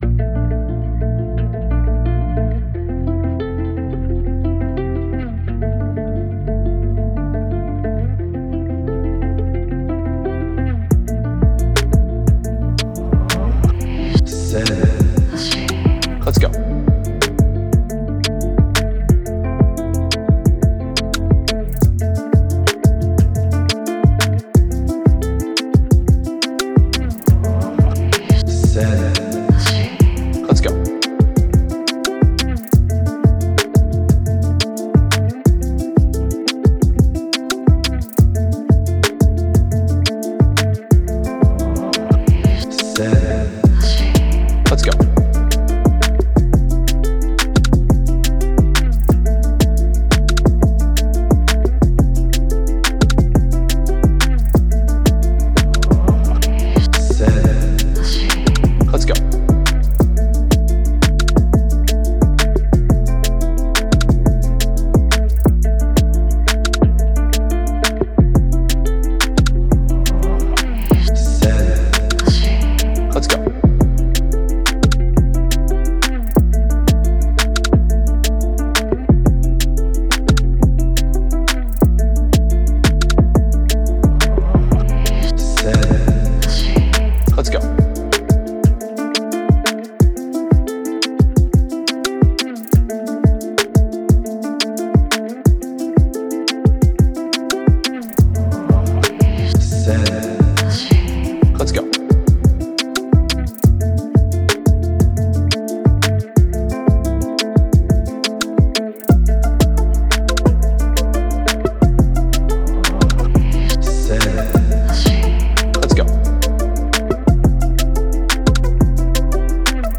Smooth – Afro Beat – Type Beat
Key: Db
176 BPM